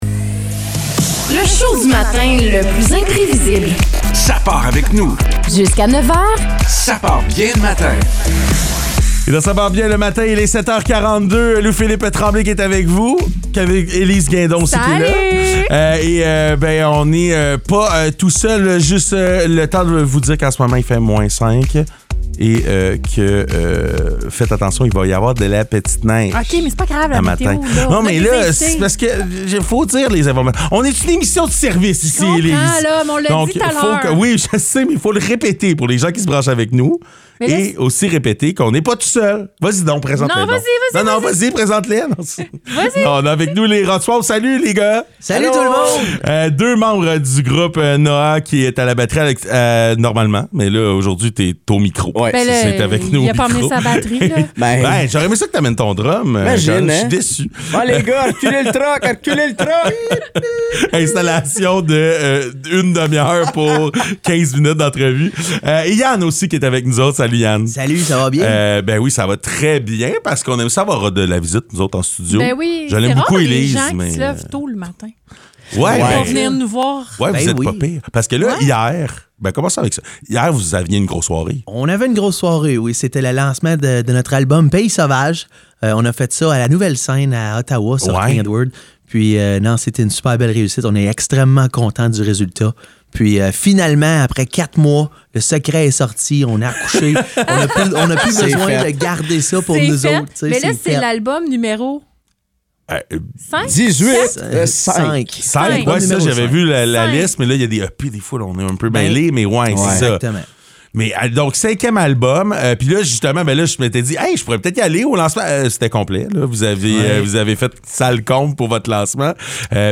Ils nous ont même fait le cadeau d'une prestation live de la chanson "Rocket".